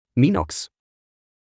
If you are wondering how the word Minox is actually pronounced,